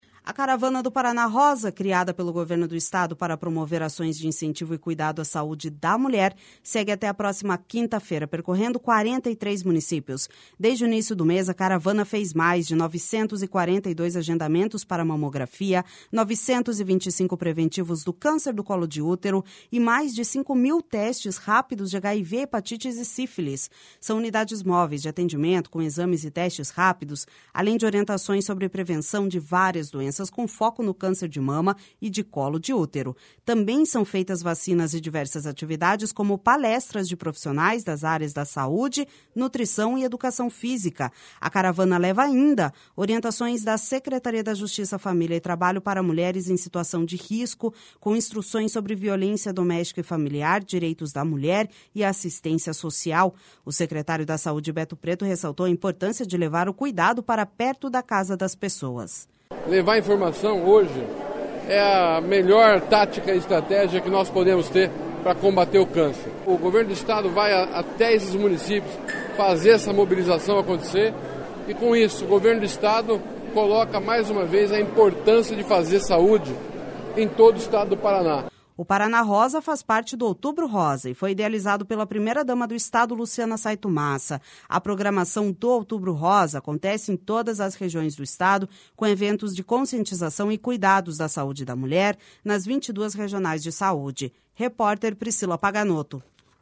O secretário da Saúde, Beto Preto, ressaltou a importância de levar o cuidado para perto da casa das pessoas.// SONORA BETO PRETO//O Paraná Rosa faz parte do Outubro Rosa e foi idealizado pela primeira-dama do estado, Luciana Saito Massa.